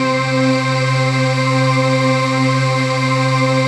DM PAD2-42.wav